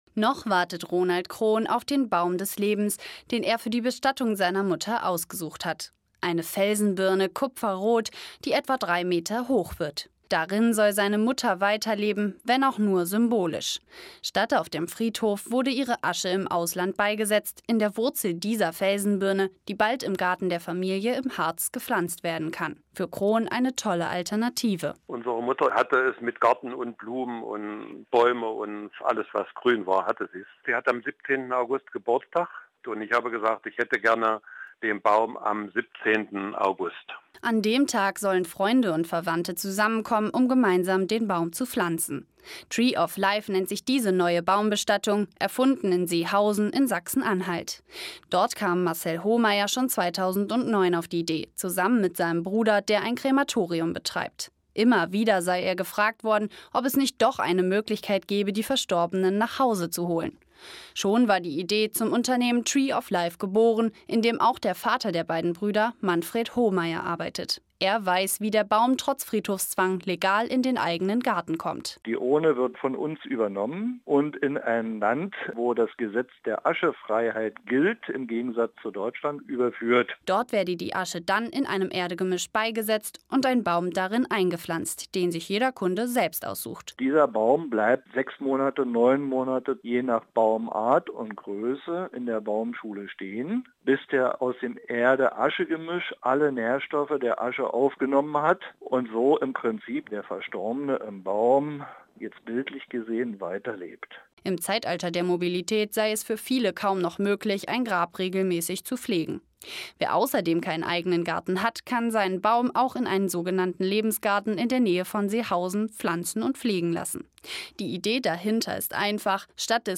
You can download the audio file in the MP3 format using this URL: You can download the audio file in the OGG / Vorbis format using this URL: Radiobeitrag zum „Tree of Life“